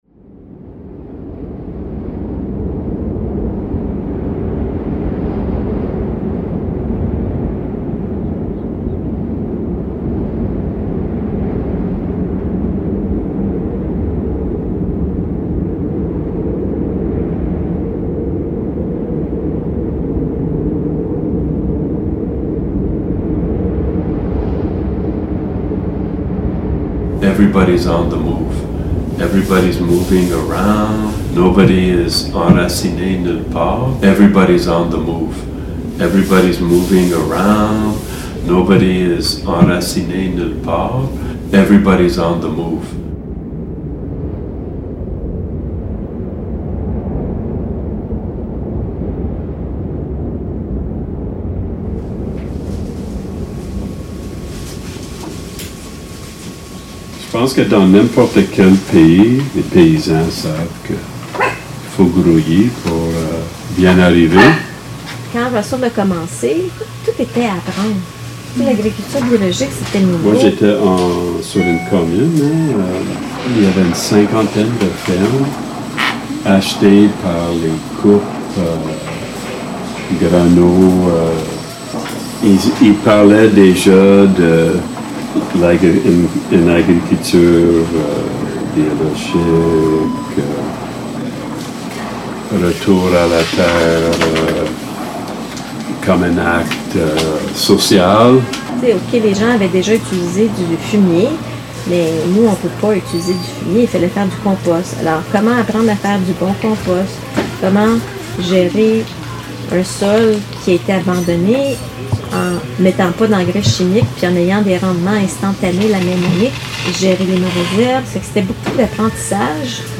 ROOTS ARE IMPORTANT AUDIODOCUMENTARY